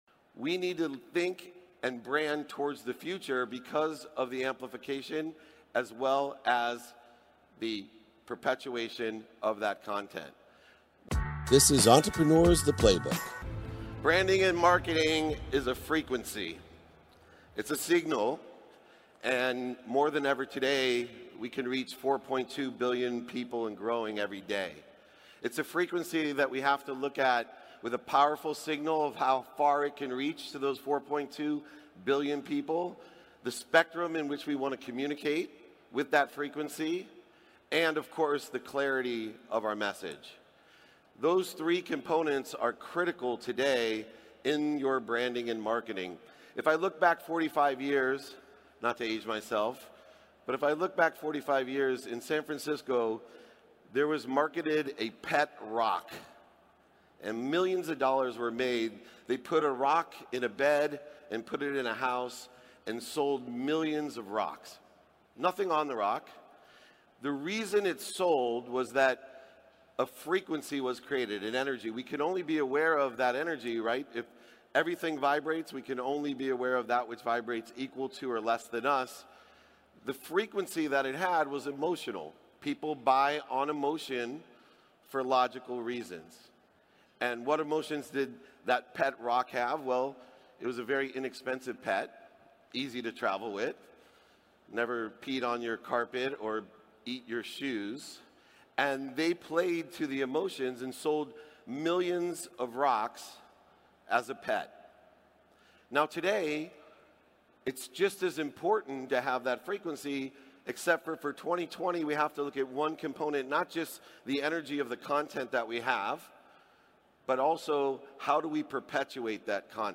Today's episode is from my keynote at Web Summit. I cover all the ways to successfully create your own brand and amplify it for the future.